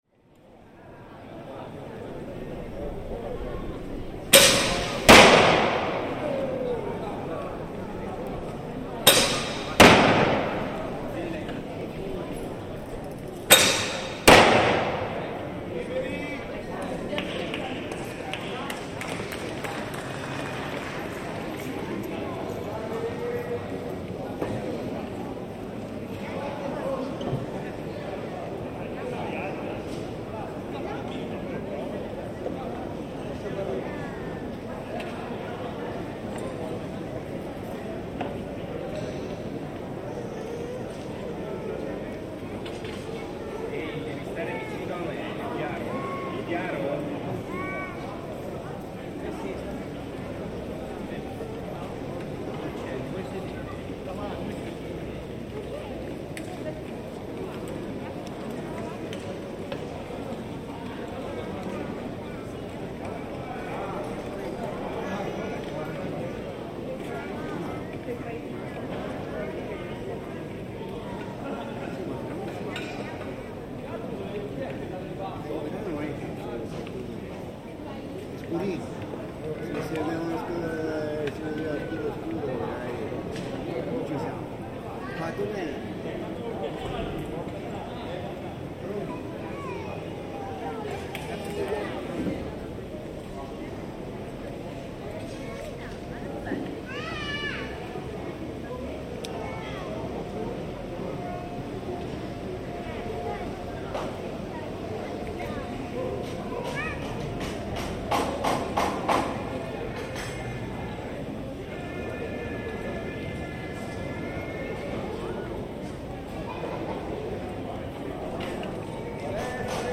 Wandering around the medieval city of Volterra in Tuscany, what could be more appropriate than entering the main piazza to the sounds of an authentic crossbow competition taking place? Here you can hear the practice session ahead of the main competition, with the thwack of bolts hitting the target on the other side of the piazza, and the sound reverberating off every wall. Midway through, the bells from the campanile start to chime to bring even more interest and atmosphere to the recording. Binaural recording by Cities and Memory.